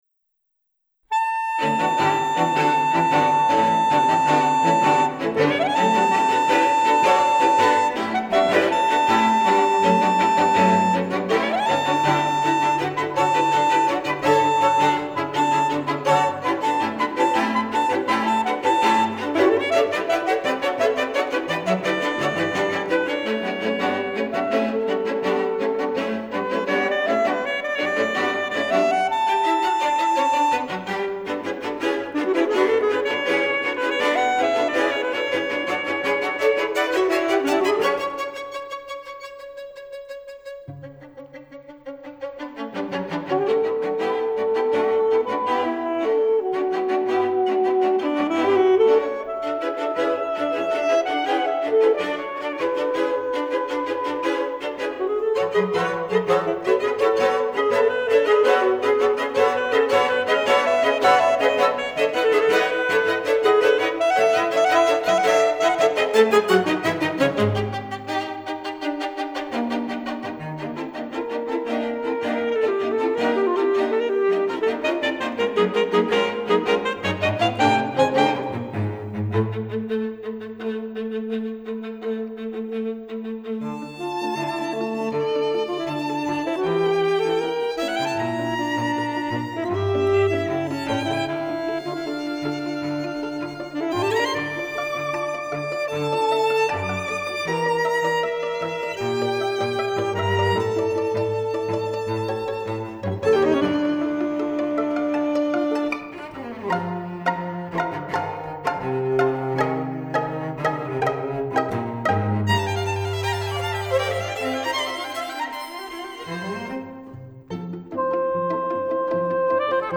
Solo Saxophone & String Quartet